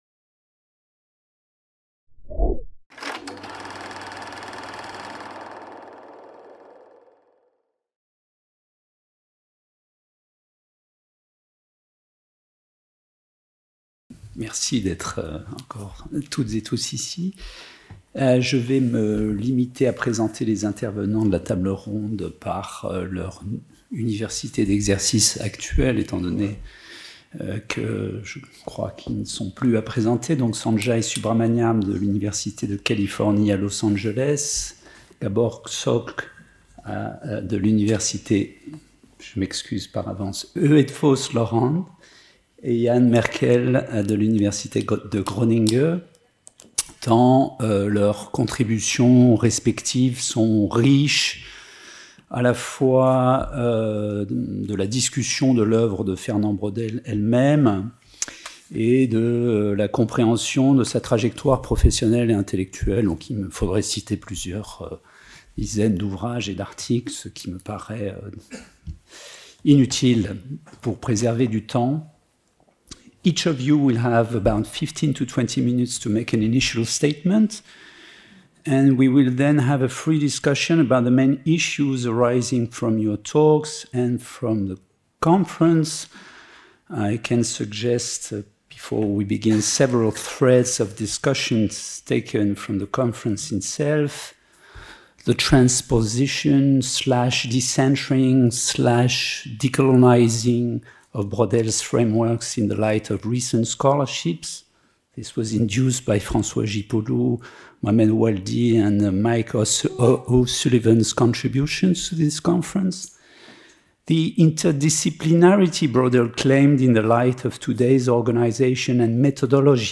Table ronde : 40 ans après | Canal U
Cinquième session du Colloque Braudel, qui s'est tenue le 28 novembre 2025 dans le grand Hall de la FMSH